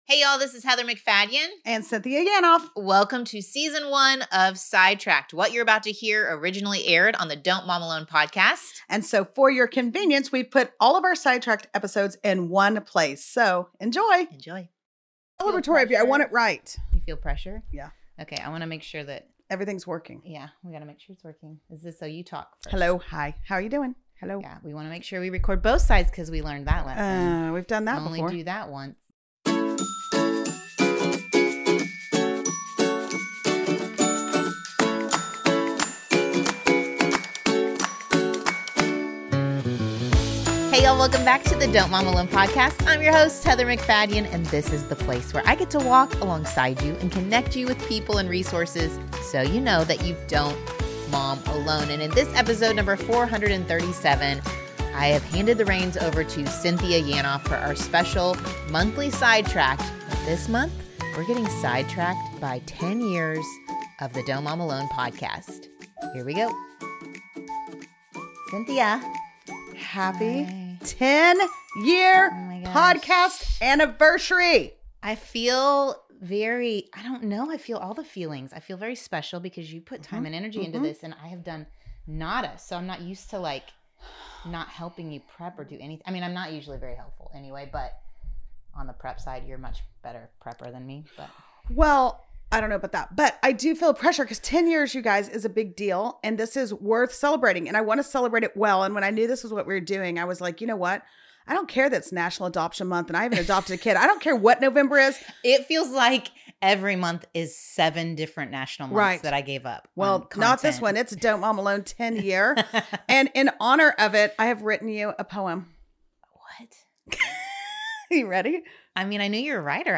Jk, there isn’t. But we do have a great lineup of special guests and listeners to help us commemorate 10 years of mom-ing together.